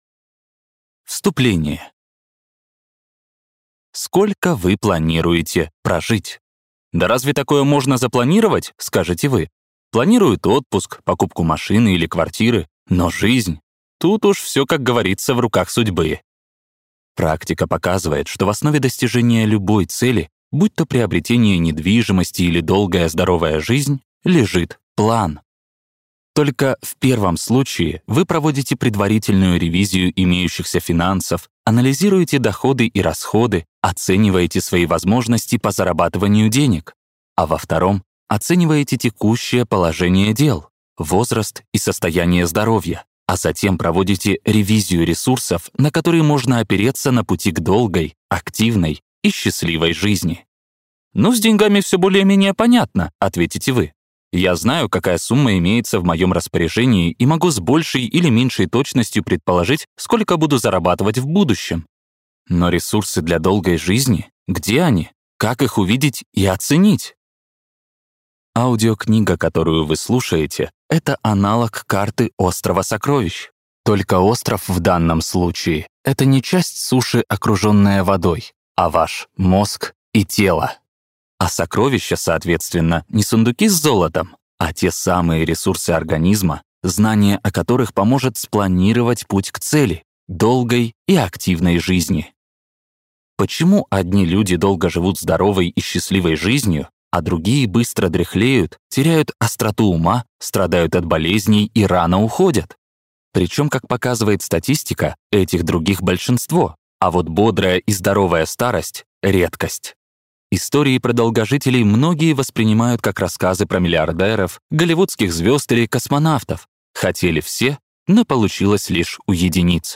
Аудиокнига Фокус на жизнь. Научный подход к продлению молодости и сохранению здоровья | Библиотека аудиокниг